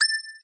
ping_4.ogg